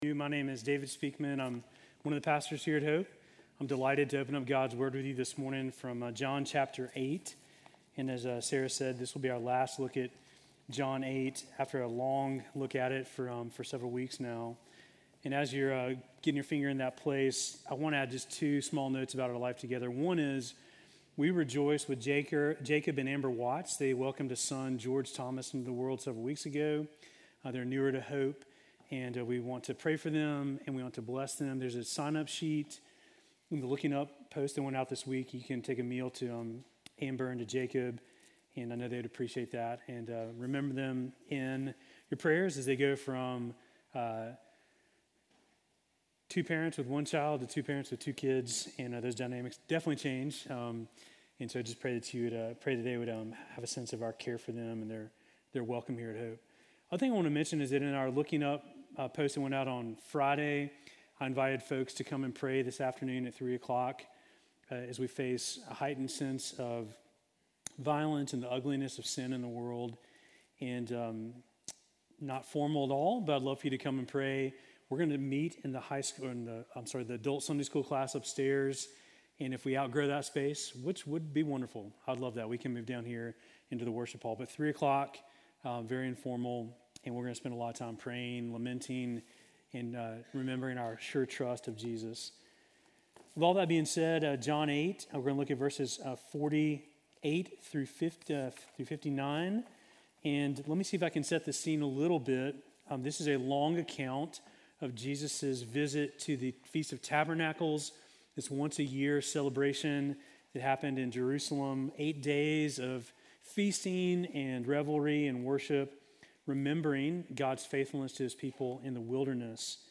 Sermon from September 21